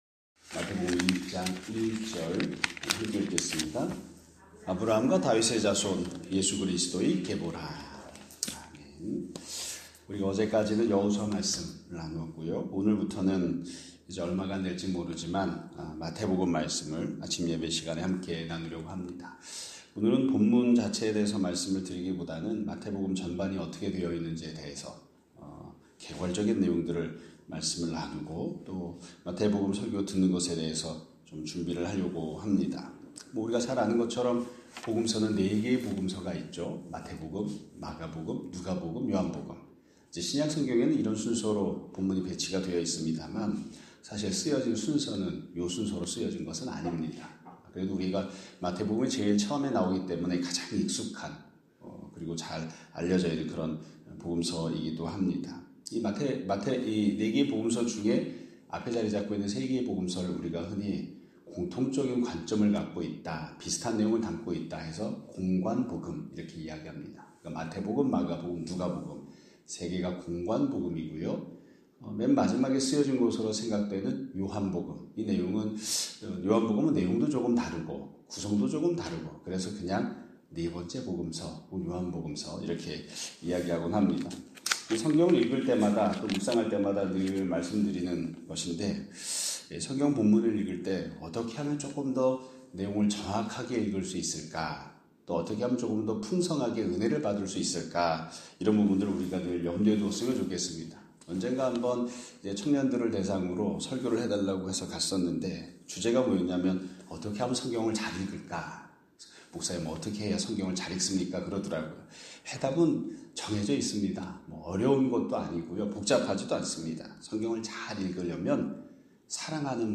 2025년 3월 18일(화요일) <아침예배> 설교입니다.